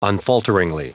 Prononciation du mot unfalteringly en anglais (fichier audio)
Prononciation du mot : unfalteringly